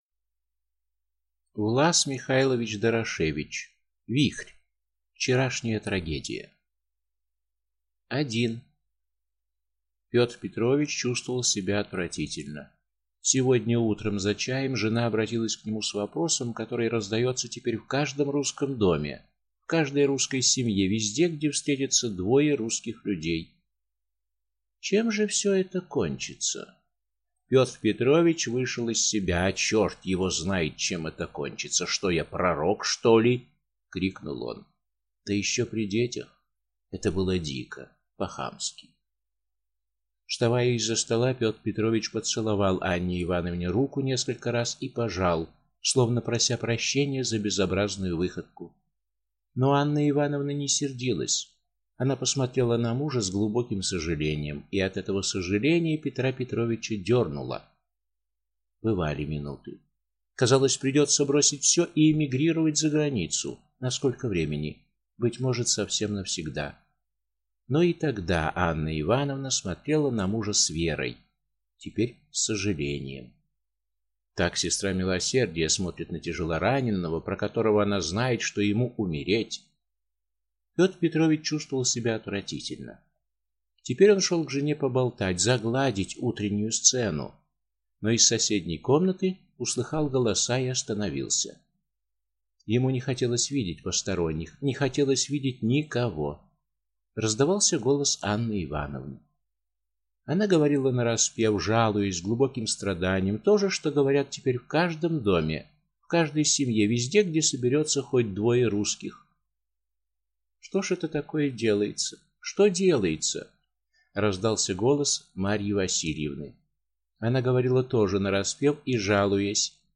Аудиокнига Вихрь | Библиотека аудиокниг